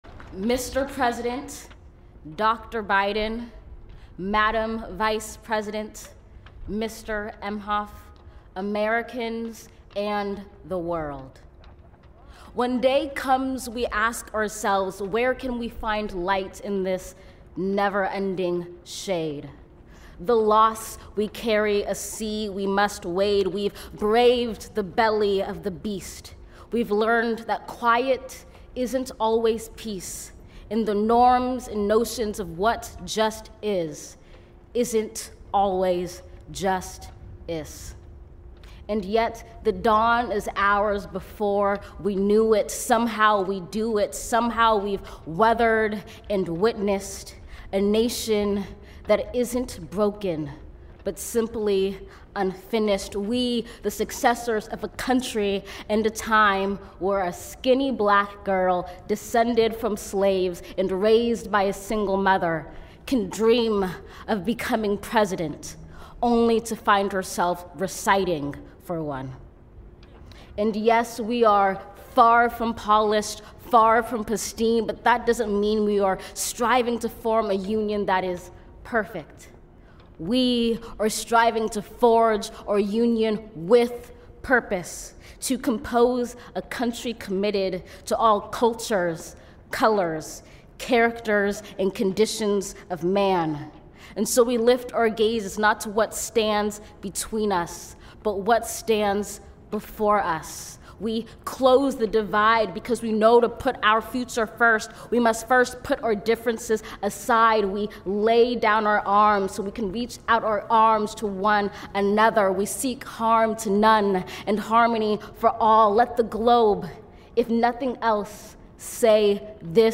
Amanda Gorman reads inauguration poem, 'The Hill We Climb' .mp3